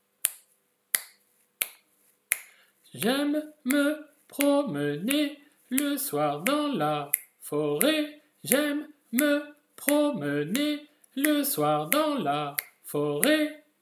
Jeu sur les hauteurs :
Hauteurs différentes
hauteurs_differentes.mp3